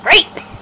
Great! Sound Effect
Download a high-quality great! sound effect.